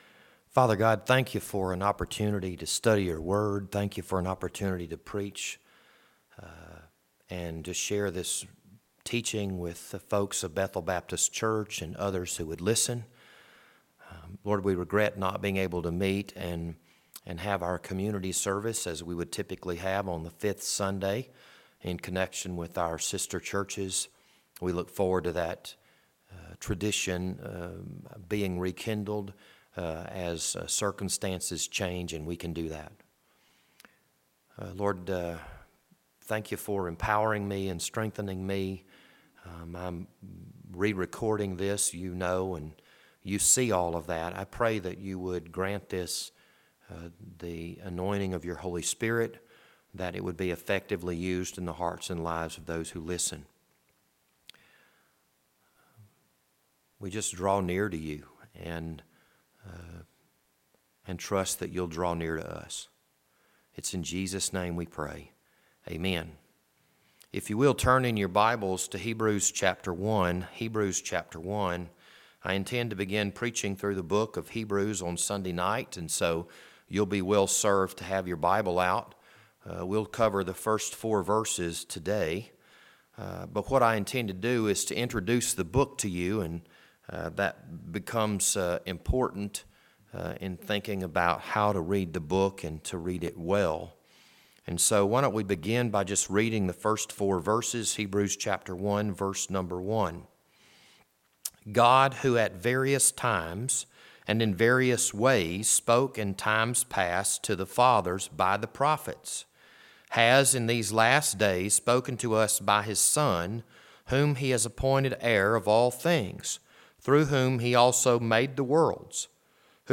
This Sunday evening sermon was recorded on March 29th, 2020.